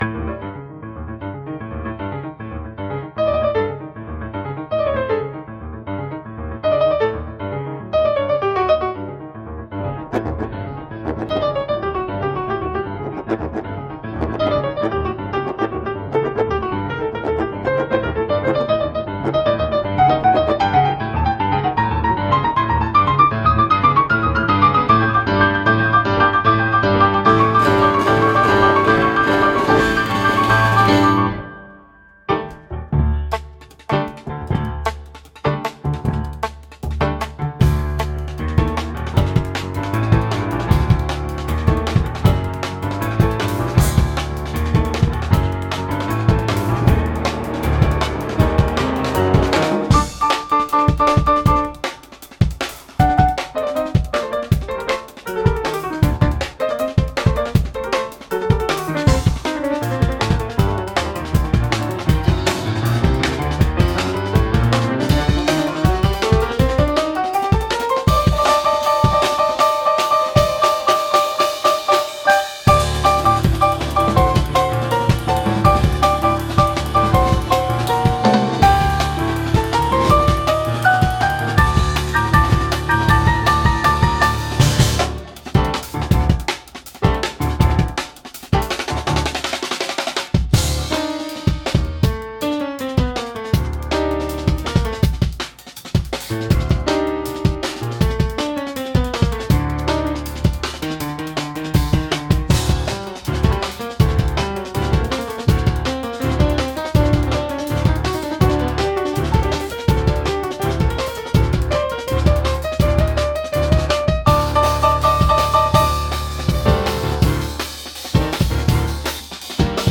Instrumental only, No vocals, Percussive and rhythmic solo piano composition with strong accents, irregular meters, and dissonant harmonies, Aggressive and energetic performance, using the piano like a rhythmic instrument, Sharp articulation and dynamic contrast